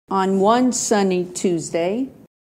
Below are some examples; notice, in the cases where one is preceded by other words, that the pitch steps up onto the word one: